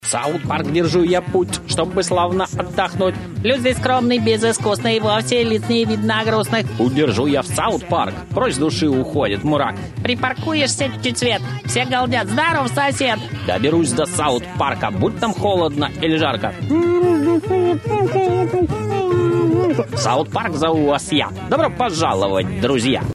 Главная тема